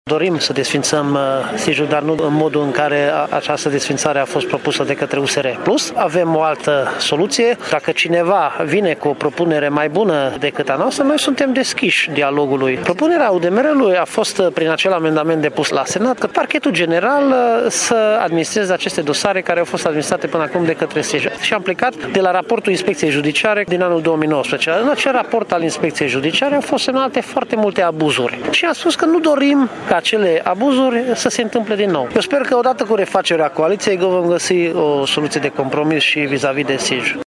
UDMR are propria soluție privind Secția Specială pentru Investigarea Infracțiunilor din Justiție, diferită de propunerea USR PLUS, a declarat astăzi la Sângeorgiu de Mureș deputatul Csoma Botond.